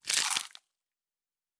Food Eat 01.wav